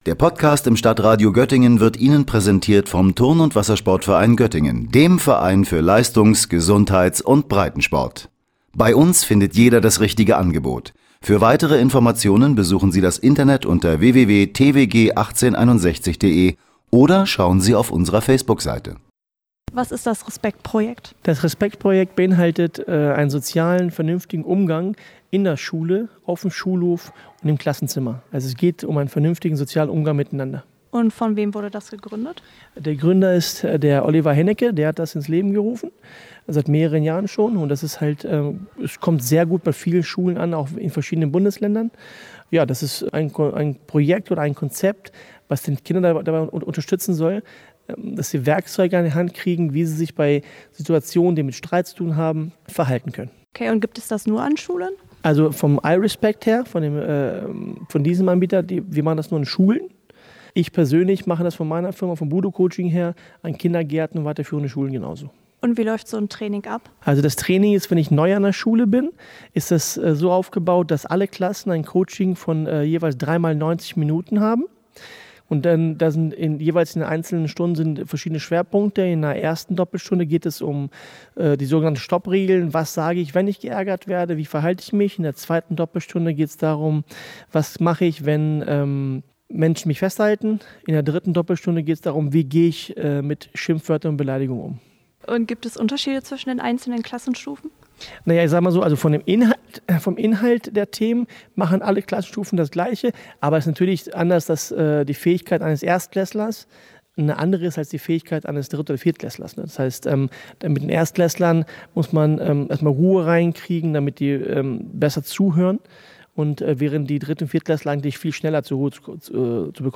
Sein Ziel ist es dabei, den Kindern sozial-emotionale Kompetenzen dem Alter entsprechend mit auf dem Weg zu geben. Auch in der Grundschule in Moringen findet derzeit ein solches Training stand.